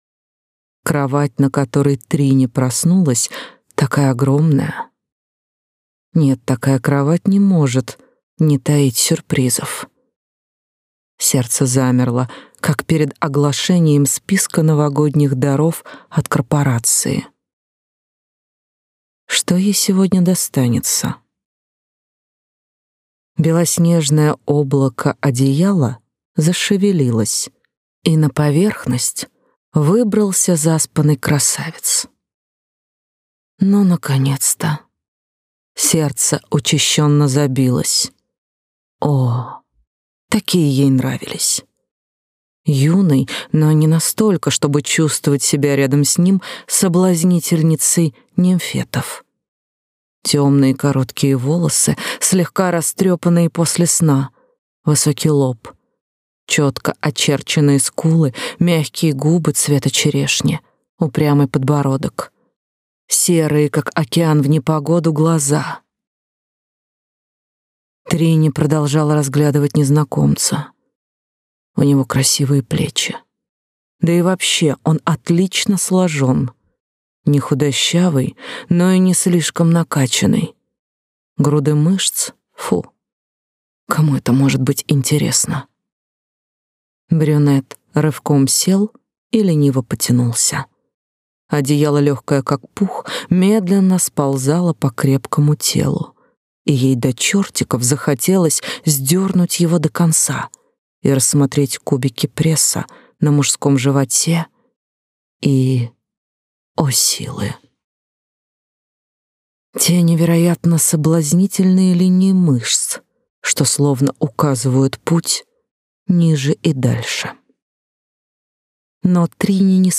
Аудиокнига Пиратская копия - купить, скачать и слушать онлайн | КнигоПоиск